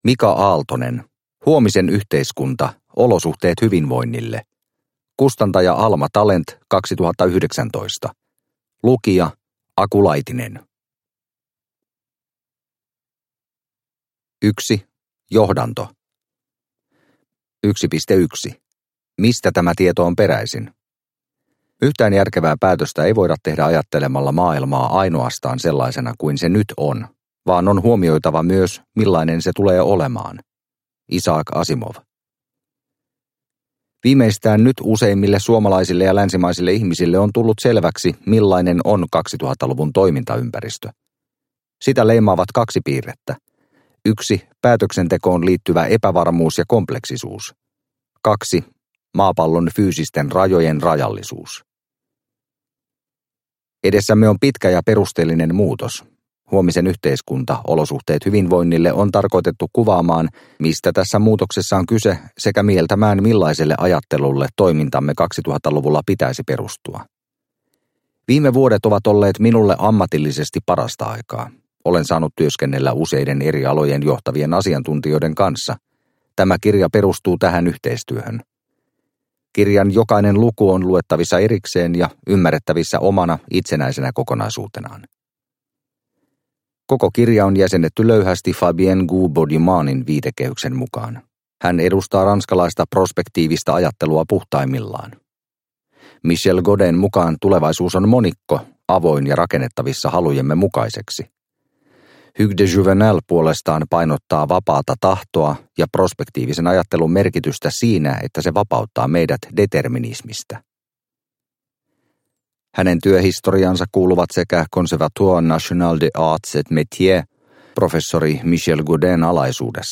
Huomisen yhteiskunta – Ljudbok – Laddas ner